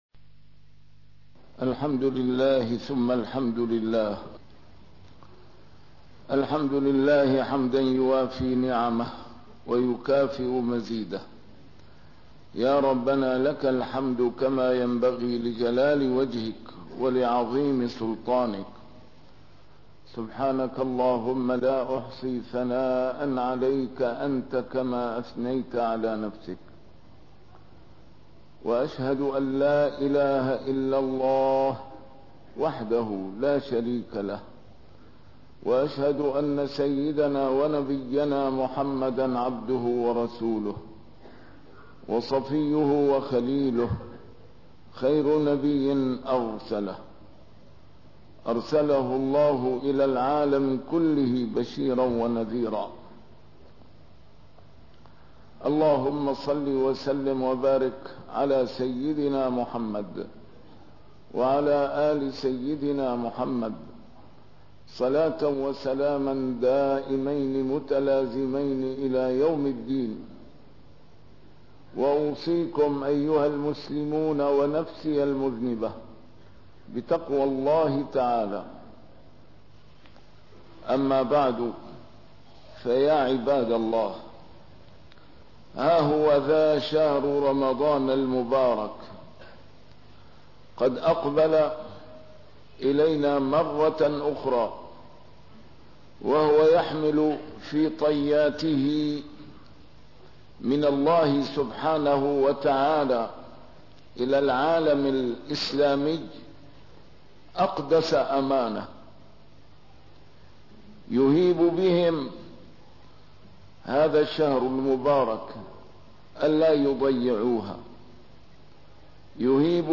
A MARTYR SCHOLAR: IMAM MUHAMMAD SAEED RAMADAN AL-BOUTI - الخطب - الأمانة الكبرى